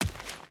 Dirt Run 1.ogg